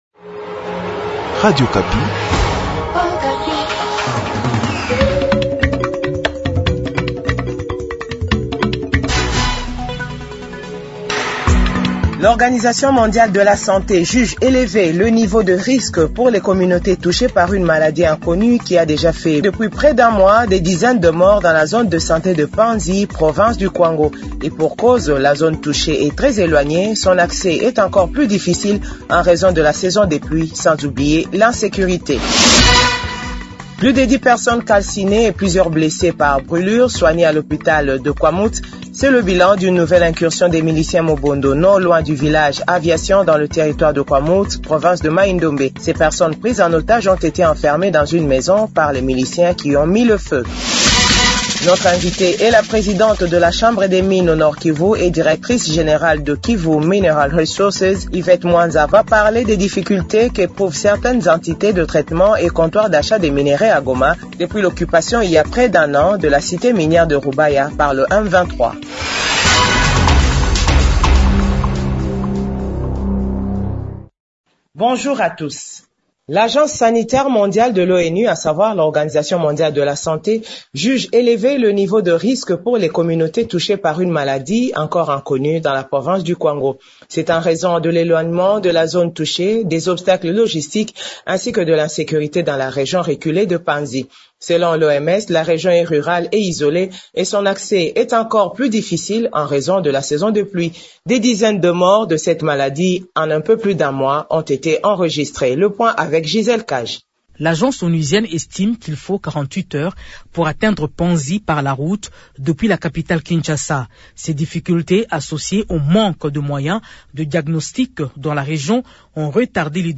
JOURNAL FRANÇAIS DE 15H00